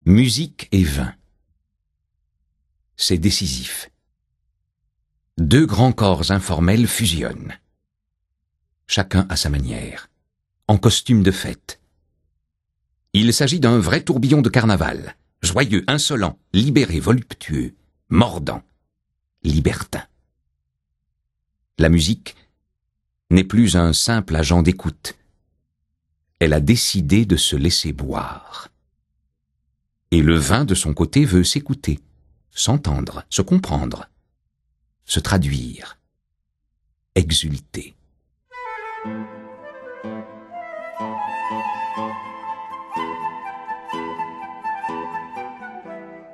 Bach, Mozart, Schubert, Sor accompagnent les lectures